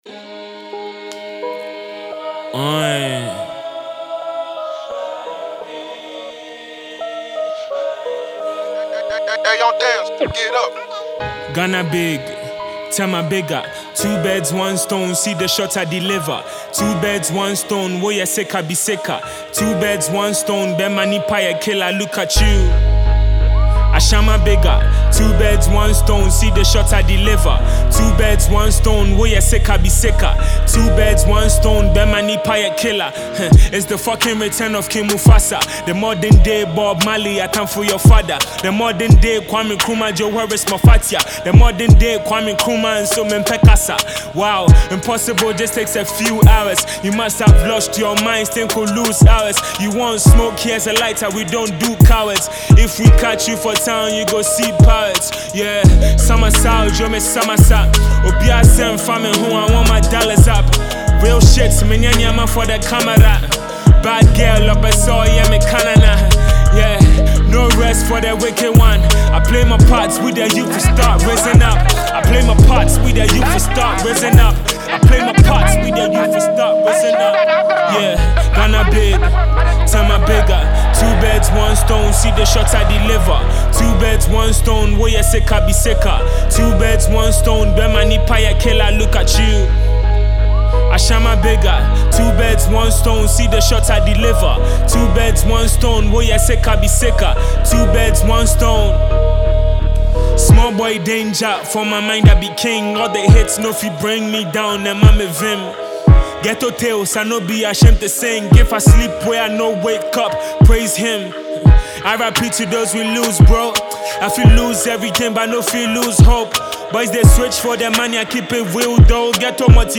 Young and award winning Ghanaian rapper